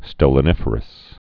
(stōlə-nĭfər-əs)